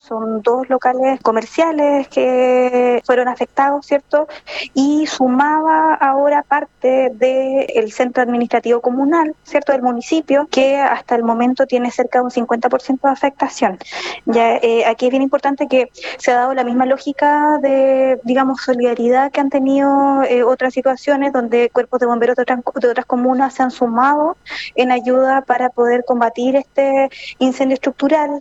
En conversación con La Radio, la delegada presidencial subrogante de Chiloé, Danitza Ortiz, detalló lo ocurrido.